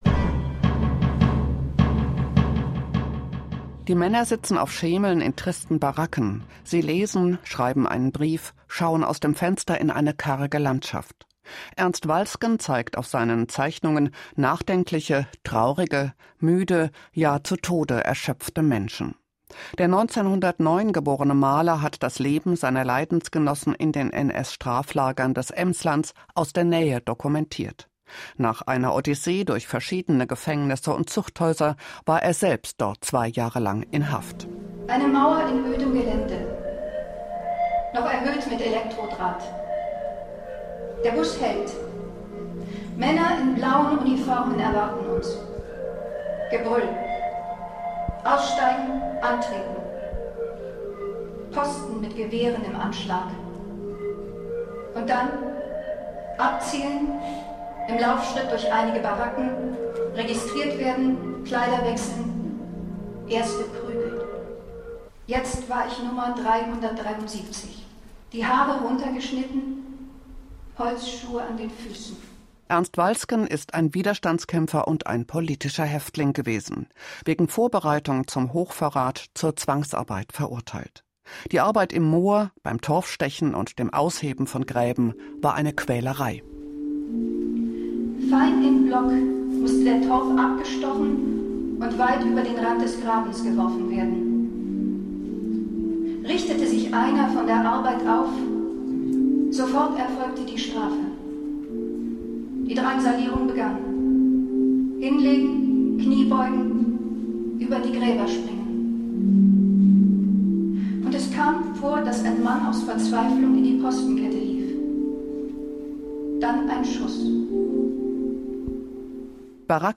Bericht